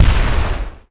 Expl03.ogg